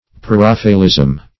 Preraphaelism \Pre*raph"a*el*ism\
preraphaelism.mp3